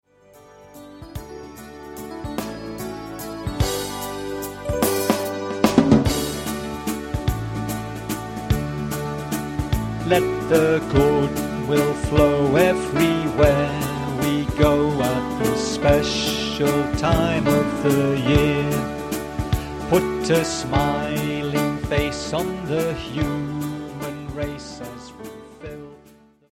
Sample from the Vocal CD